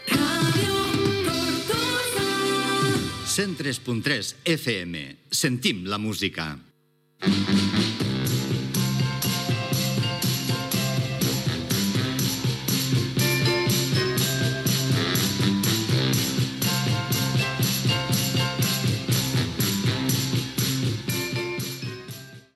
Indicatiu de l'emissora i tema musical.